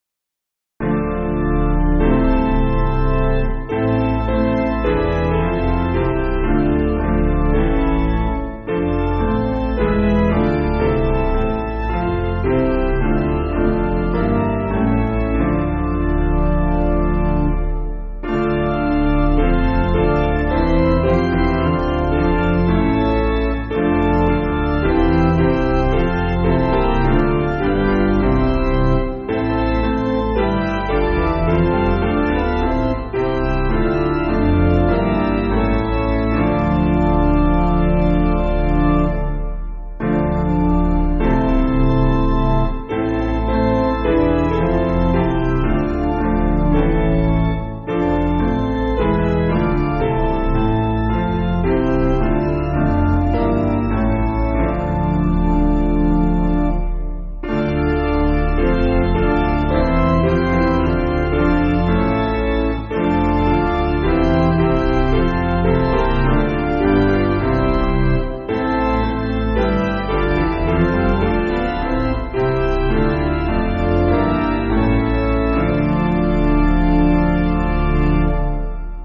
Basic Piano & Organ